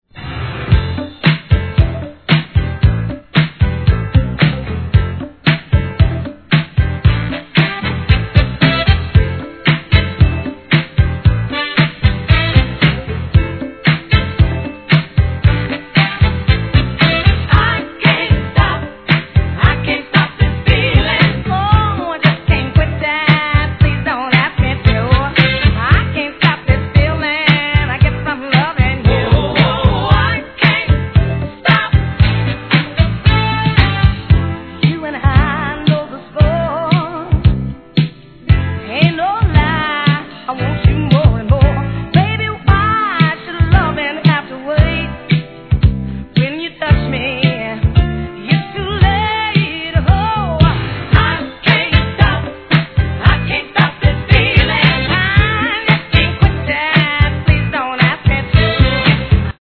SOUL/FUNK/etc...
DANCE CLASSIC